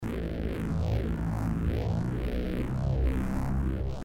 标签： 69 bpm Crunk Loops Brass Loops 1.16 MB wav Key : Unknown
声道立体声